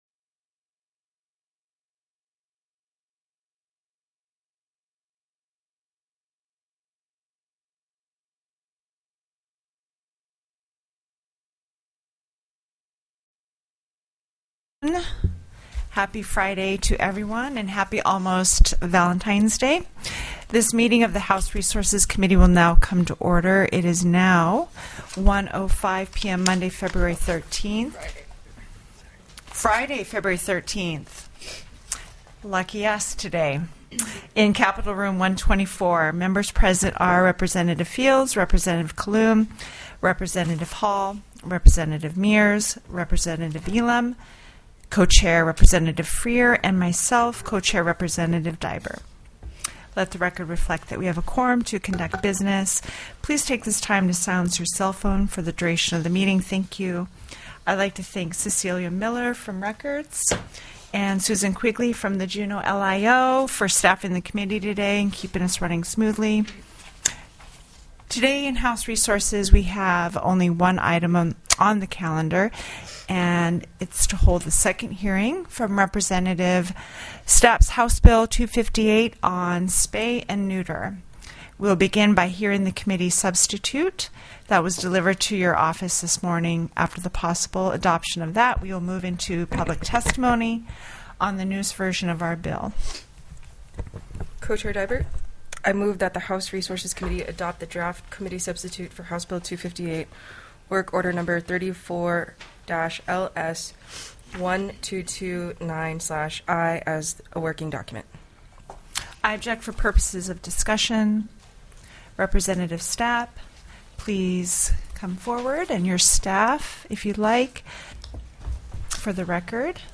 The audio recordings are captured by our records offices as the official record of the meeting and will have more accurate timestamps.
HB 258 STATEWIDE SPAY & NEUTER PROGRAM TELECONFERENCED